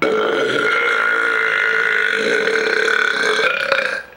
Belch.wav